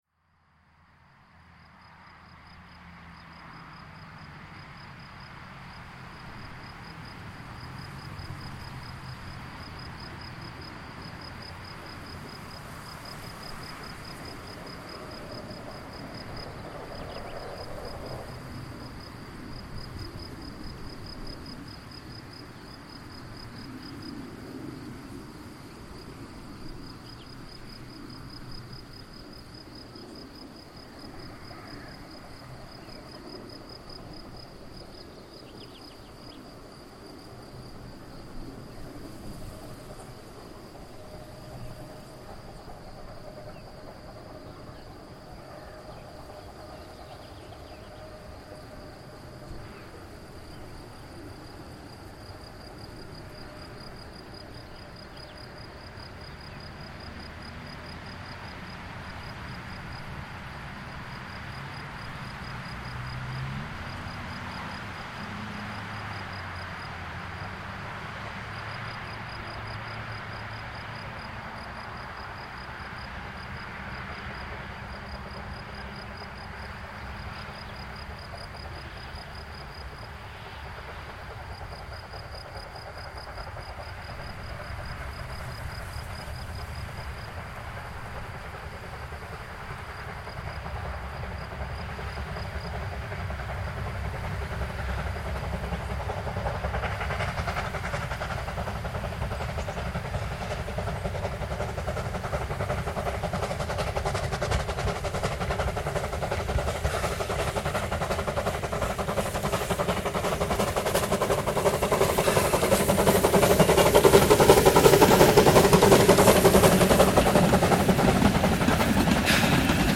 Die folgenden 3 Szenen sind bei diesem Versuch nun insofern als einigermaßen hinnehmbar zu bezeichnen.
Für diese und die nächste Szene stand das Aufnahmegerät nun weiter oben, schon deutlich leiser ist die Straße. Ebenso hört man auch deutlich, wie nicht weit hinter den Mikrofenen der Sound endet, weil Regler zu...;-)
W.N. 12 Tv mit Zug P4 von Katzenstein→Neresheim, zwischen den Wiesen/Feldern kurz vor der Einfahrt von Neresheim, um 14:18h am 19.05.2024.   Hier anhören: